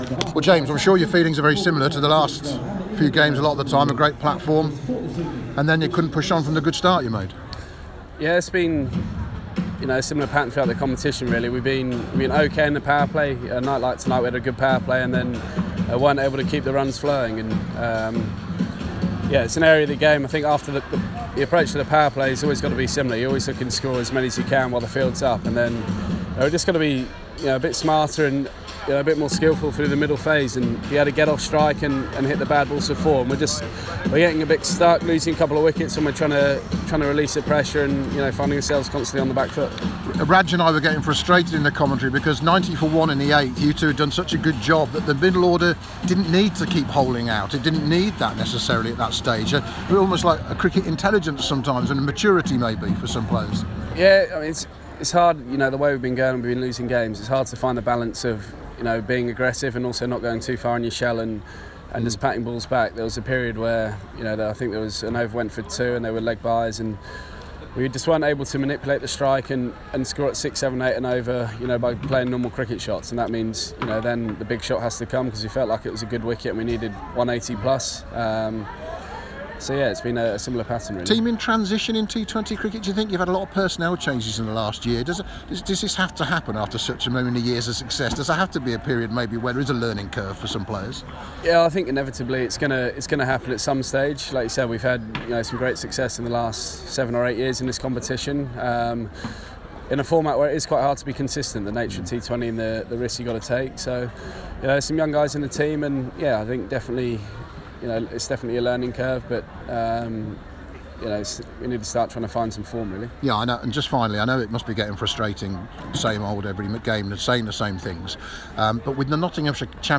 REACTION: James Vince speaking